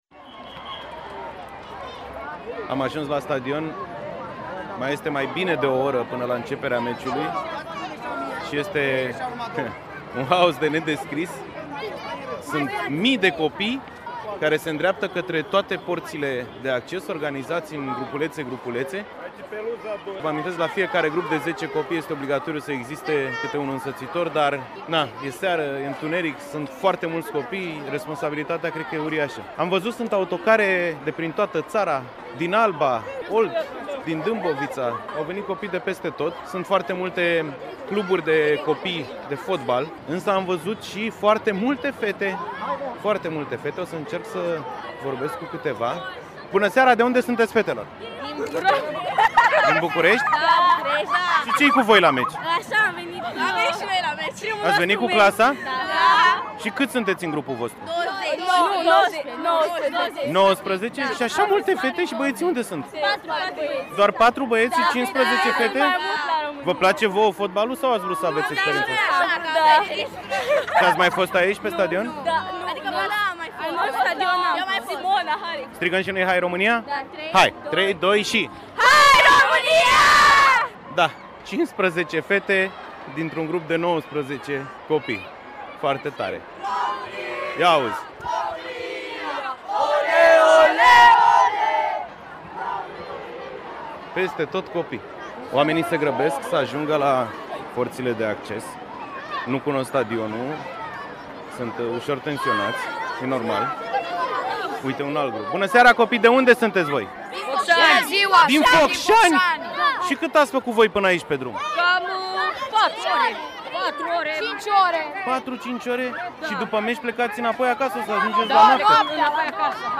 Cum au trăit 30.000 de copii și însoțitori, pe Arena Națională, meciul de fotbal România – Norvegia